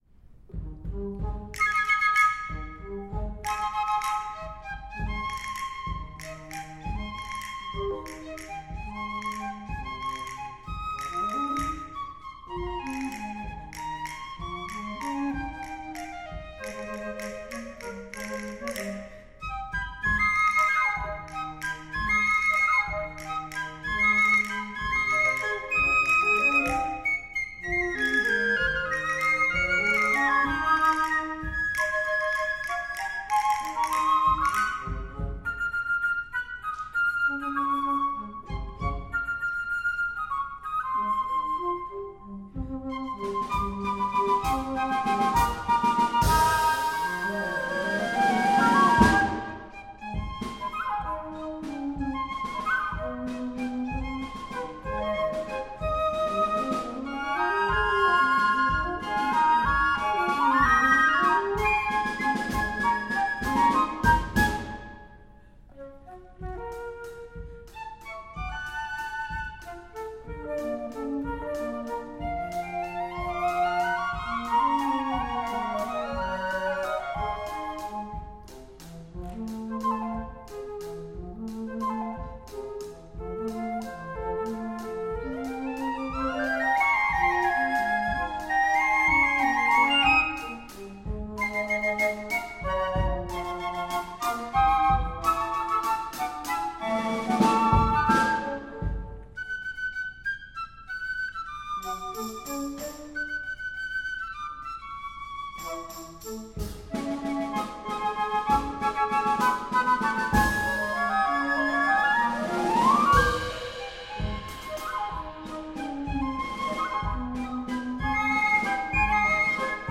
Arranged for Flute Quartet